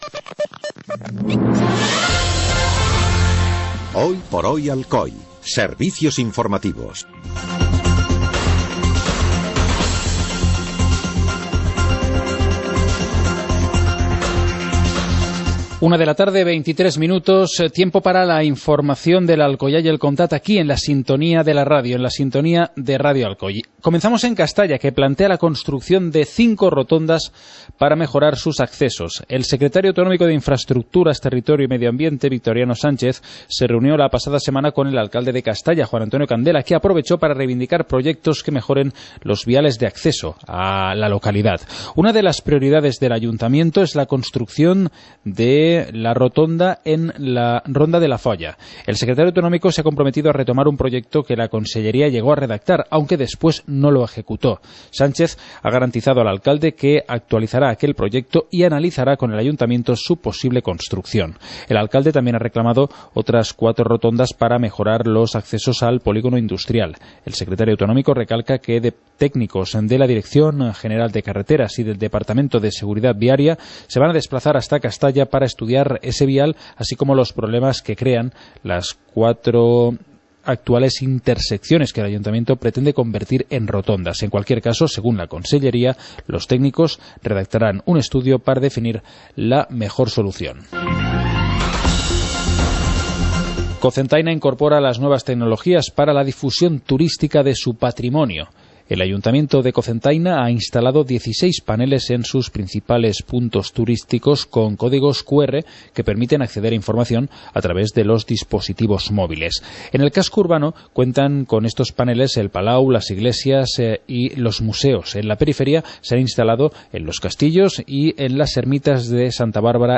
Informativo comarcal - martes, 19 de agosto de 2014